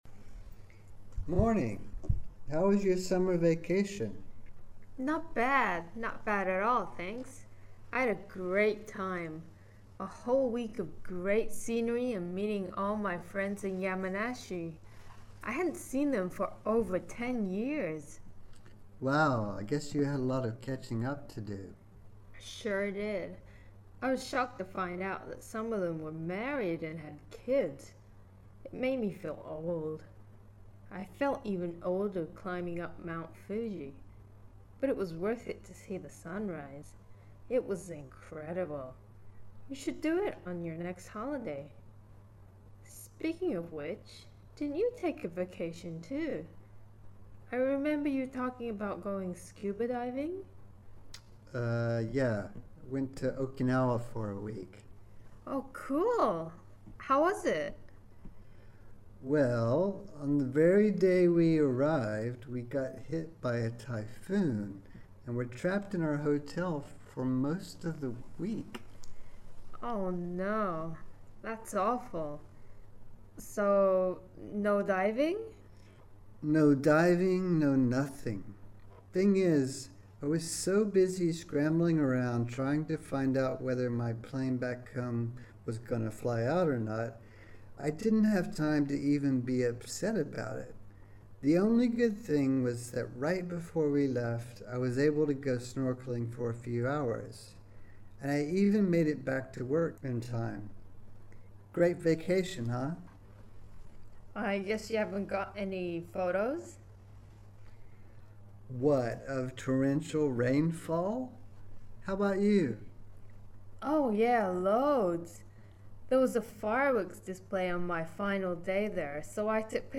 全部の音声(ゆっくり）